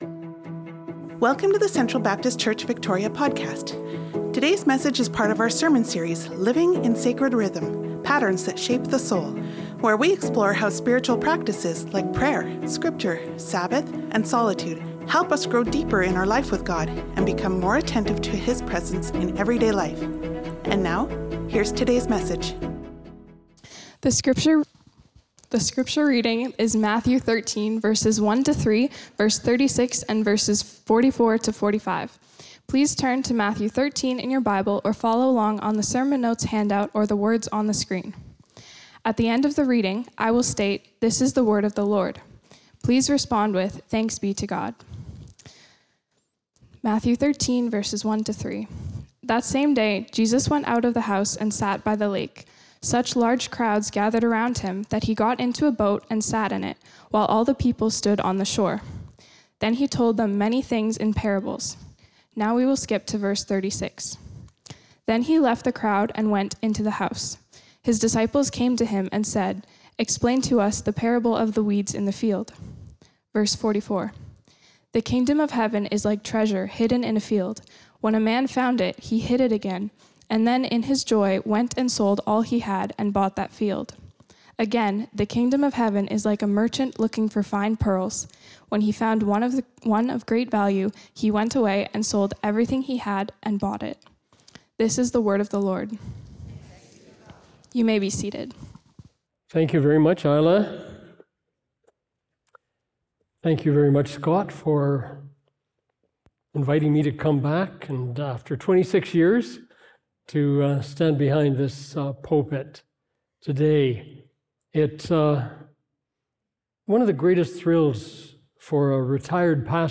Sermons | Central Baptist Church
January 4, 2026 Guest Speaker Download Download Reference Matthew 13:1-3, 36, 44-46 Sermon Notes Jan 4'26.